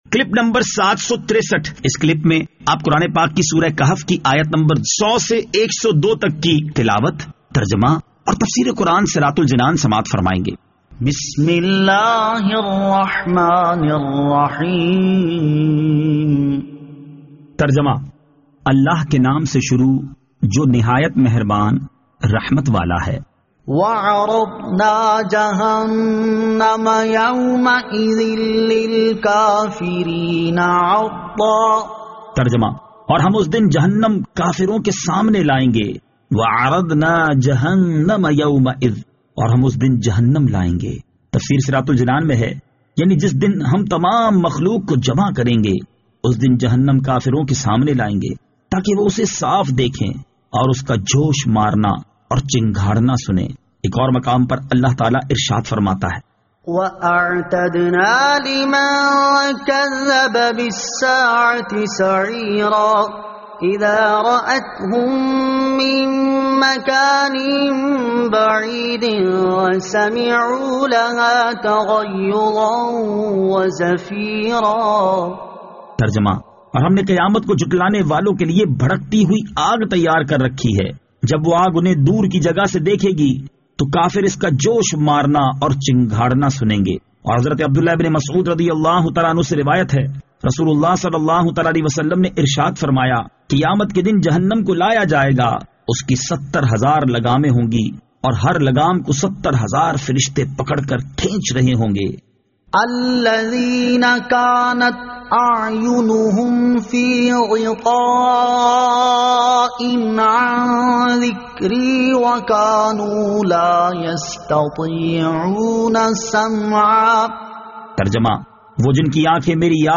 Surah Al-Kahf Ayat 100 To 102 Tilawat , Tarjama , Tafseer